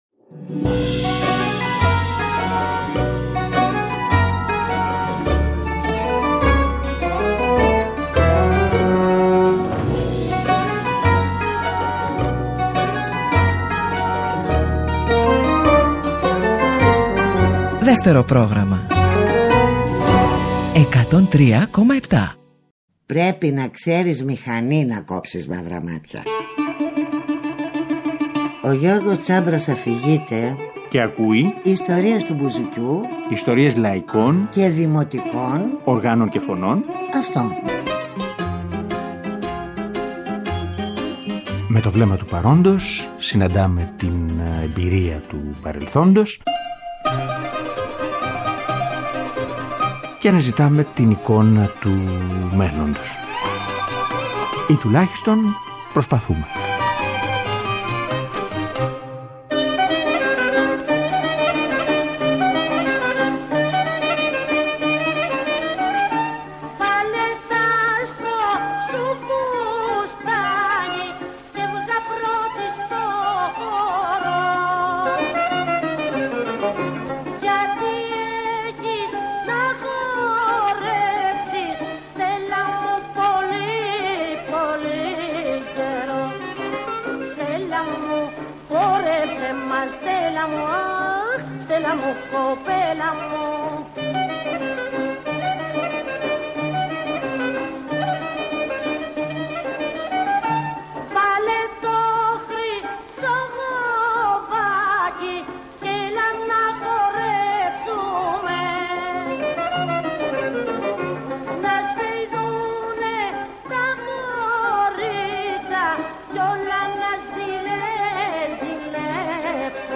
Ηχογραφήσεις από τα χρόνια του ’30 μέχρι και τα χρόνια του ’60, δημοτικά και «δημοτικά», με τρείς χαρακτηριστικές «φωνές».
δημοτικά τραγούδια